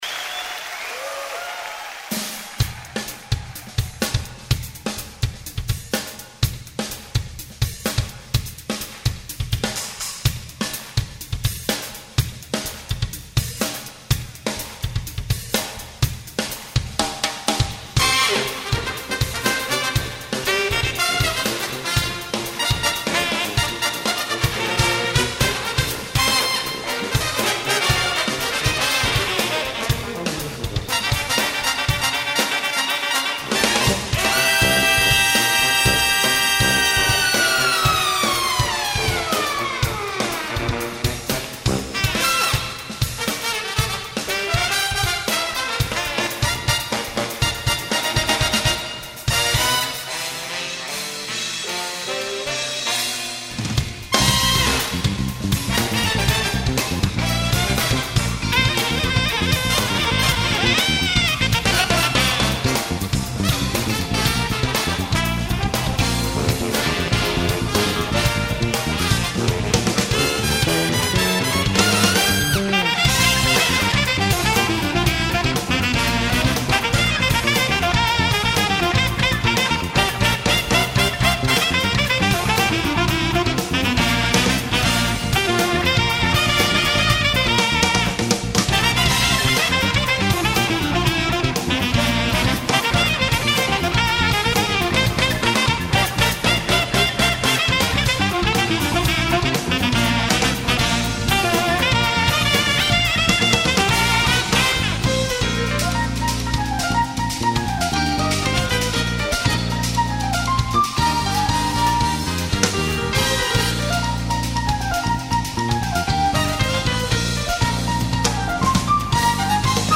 2007-05-25 오후 7:23:00 원곡의 그루브를 뒤의 브라스밴드가 열심히 흉내내는 정도..?